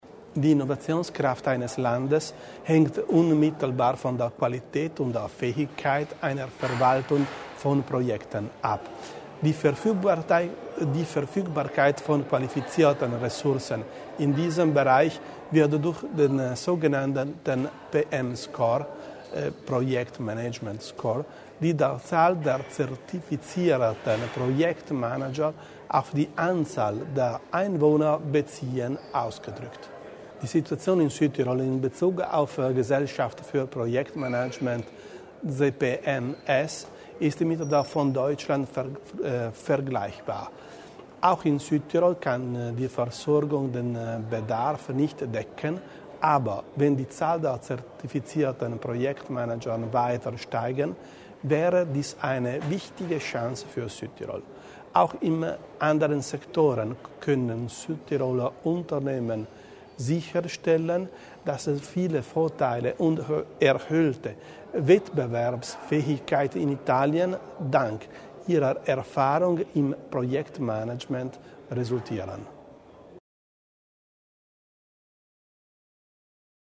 Landesrat Bizzo über die Möglichkeiten, die in der Innovation stecken
Projekte schaffen Innovation: Unter diesem Motto stand heute (29. September) im Mittelpunkt einer Tagung an der Eurac in Bozen.